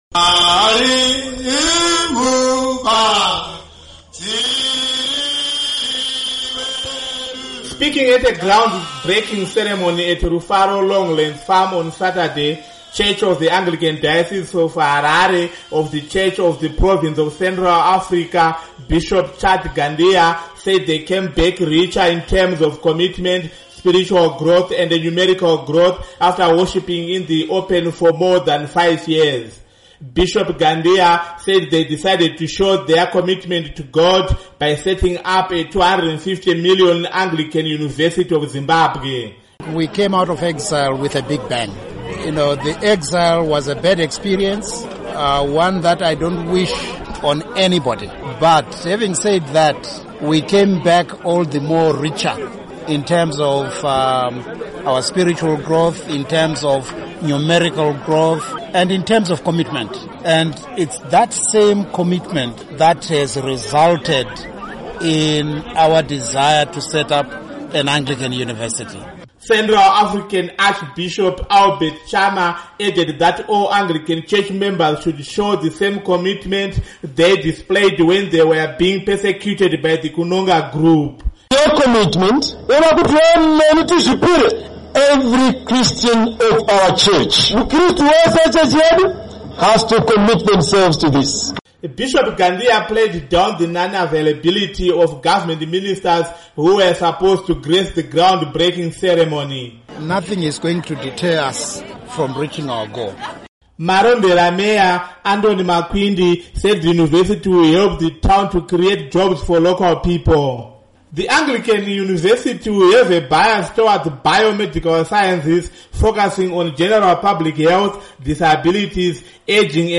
Speaking at a groundbreaking ceremony at Rufaro Longlands Farm, Bishop Chad Gandiya, said after years of persecution, they came back richer in terms of commitment, spiritual and numerical growth.